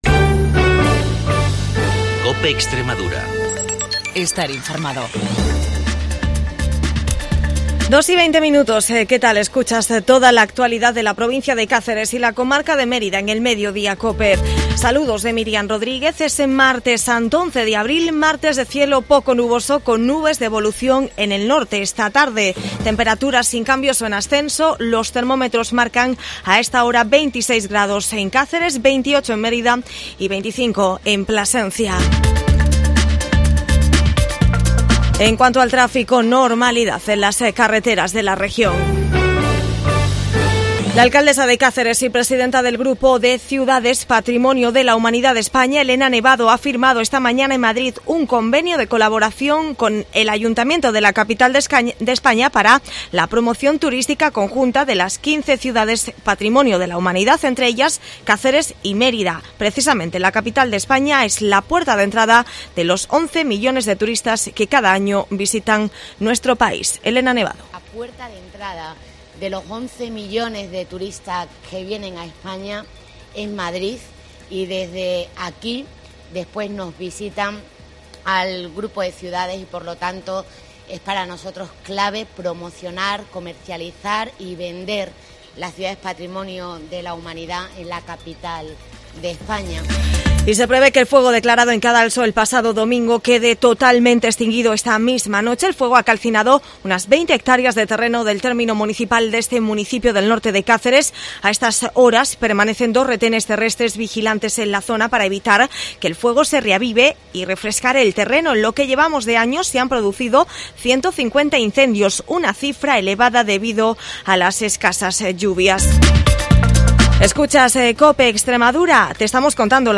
AUDIO: INFORMATIVO CADENA COPE DIA 11 DE ABRIL EN CÁCERES